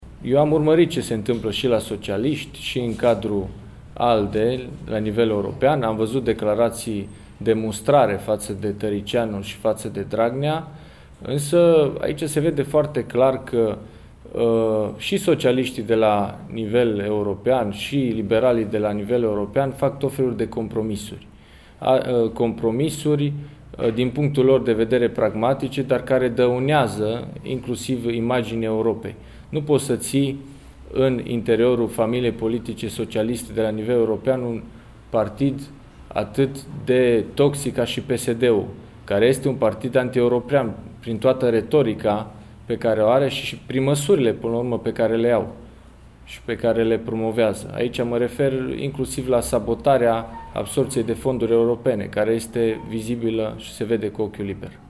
În cadrul conferinței de presă Secretarul General al PNL a menționat și despre situația din Partidul Popular European, unul de centru-dreapta european și unul din cel mai mari partide transnaționale reprezentate în Parlamentul Uniunii Europene.